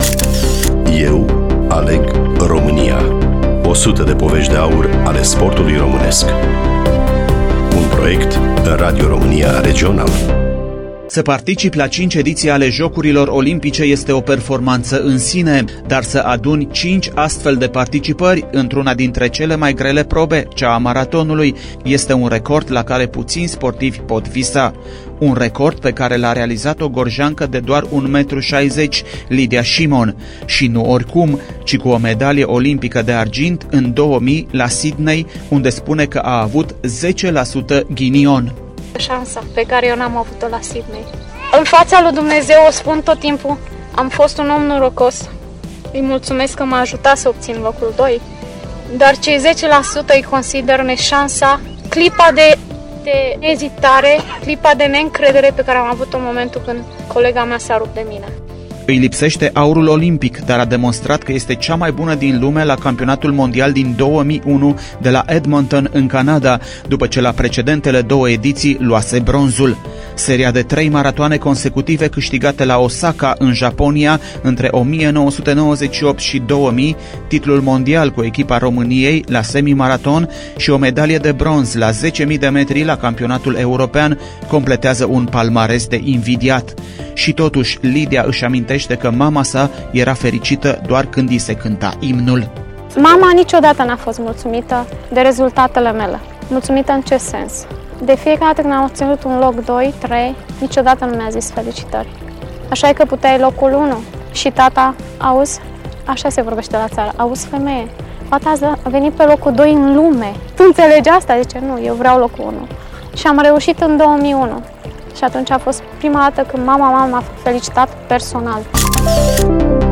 Studiul Radio Romania Timisoara